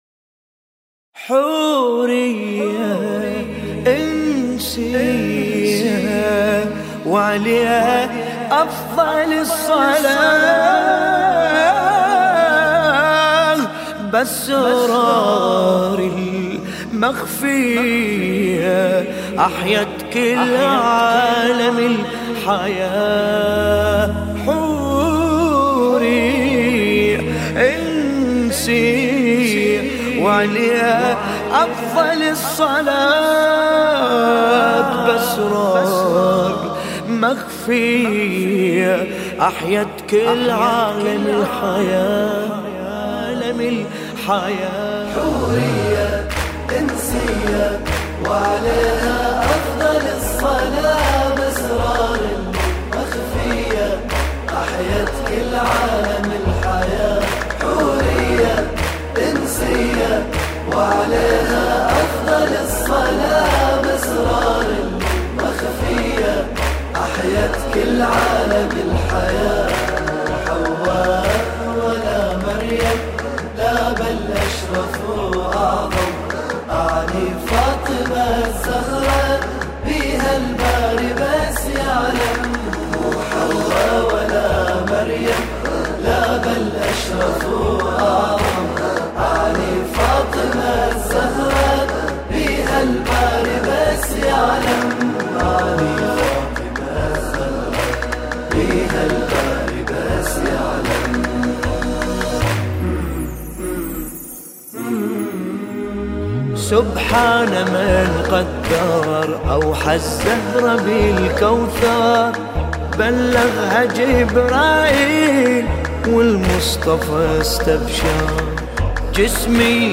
مولودی